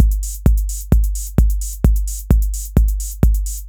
OSH Mat 1ch Point Beat_130.wav